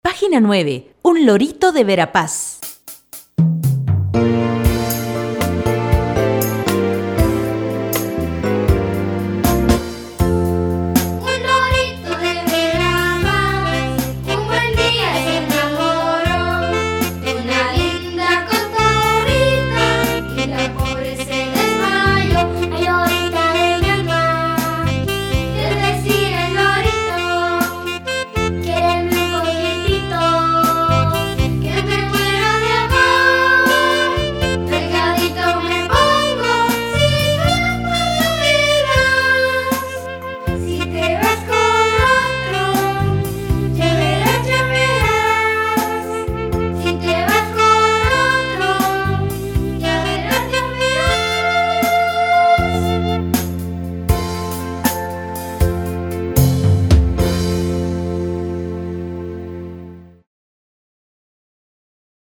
Son tradicional